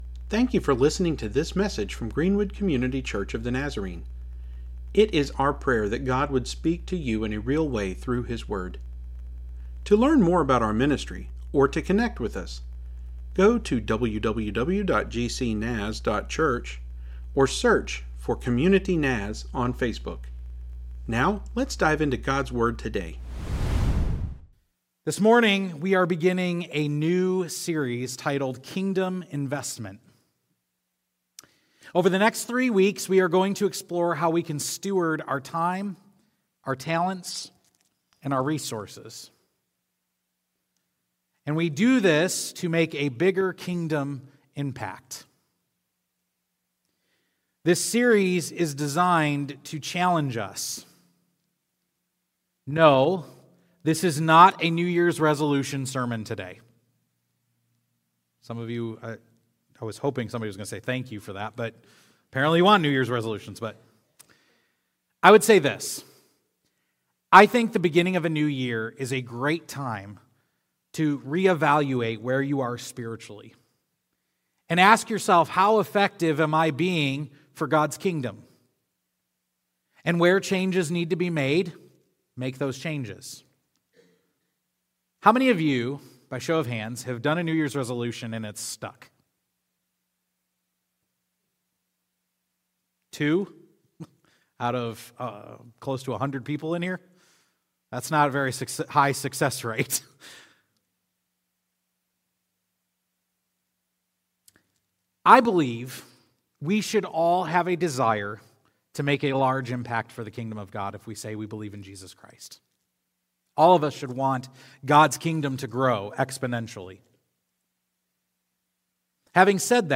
Thank you for joining us for worship this morning.
Jan sermon edited.mp3